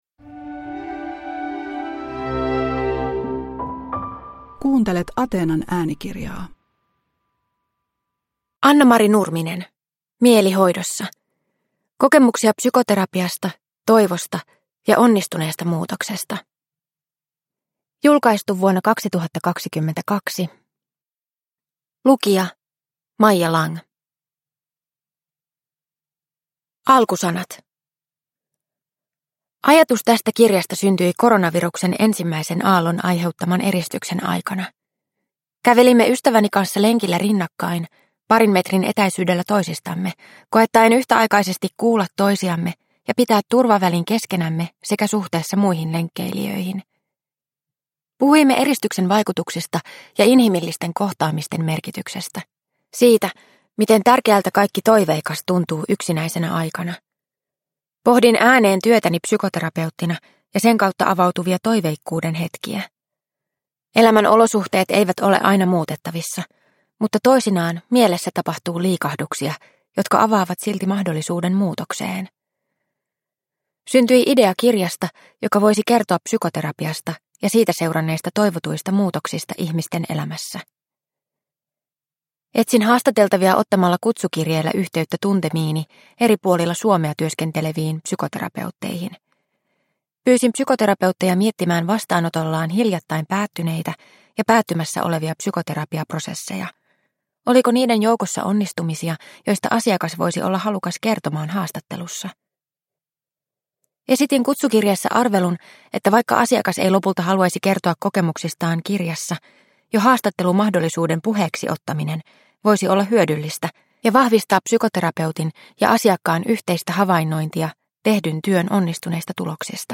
Mieli hoidossa – Ljudbok – Laddas ner